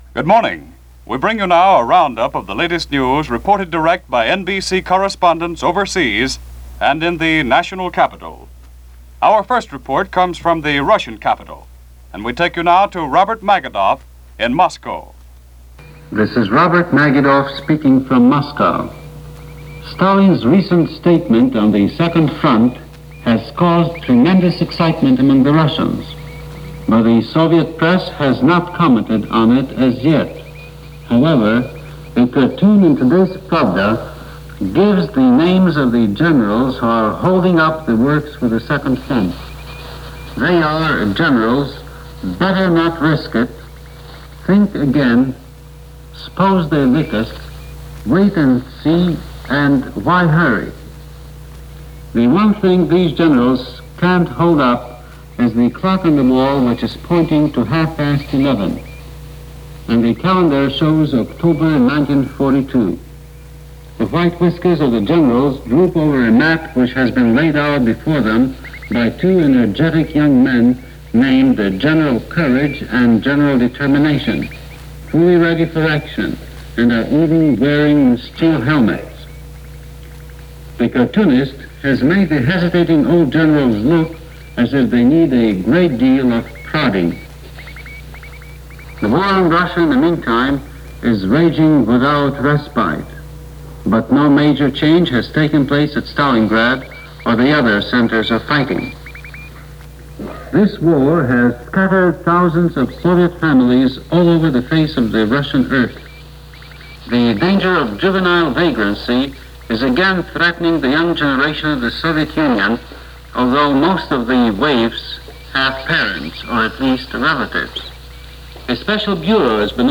News-October-6-1942.mp3